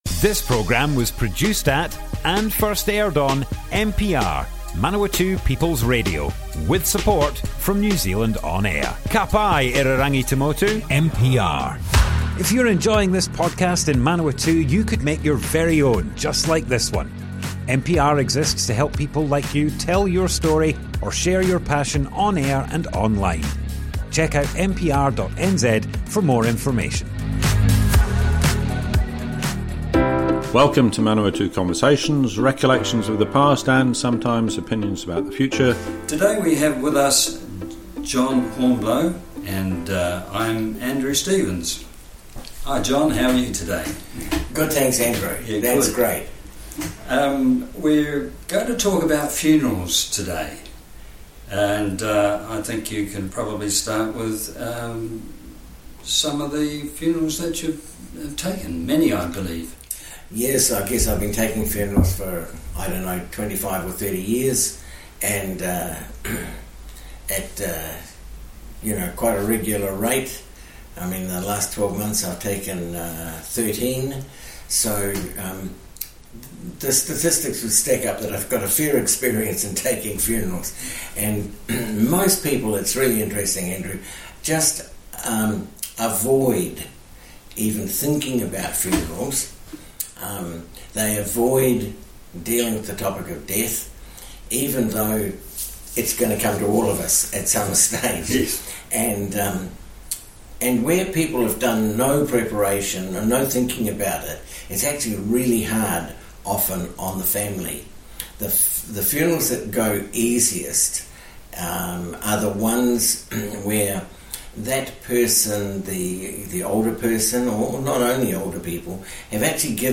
Manawatu Conversations More Info → Description Broadcast on Manawatu People's Radio, 25th April 2023.
oral history